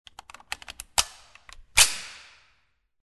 Звук перезарядки снайперской винтовки